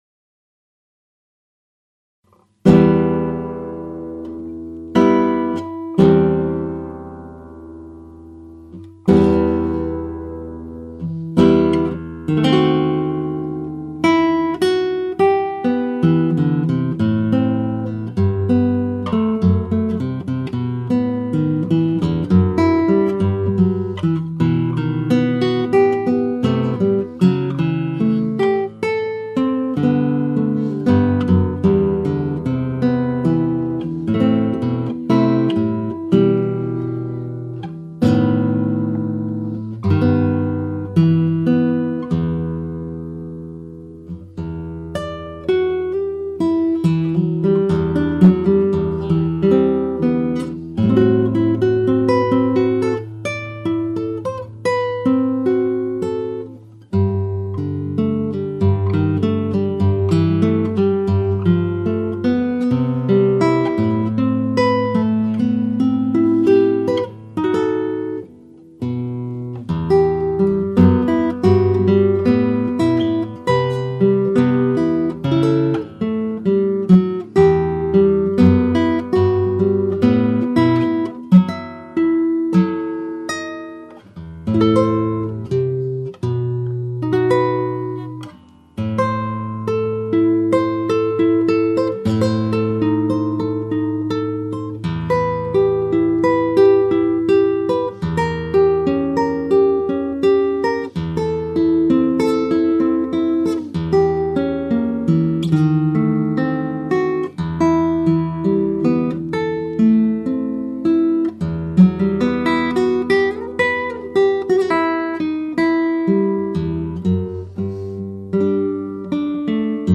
Klassikalist kitarri mängides komponeerin oma lugudesse elemente rokist, popist, džässist, bluusist – mängin seda kõike klassikalisele kitarrile omase tehnika ja tooniga, jättes alles klassikalise muusika printsiibid.
Seekordne lugu on pisut rubato’lik – intros mõtlikud akordid, millest välja kasvav bachilik sammuv meloodia laheneb toonikasse.
Kõlaliselt on see muusika oma helikeelelt romantismi kalduv – ülekaalus on teatud muusikalised elemendid, mis sellele viitavad.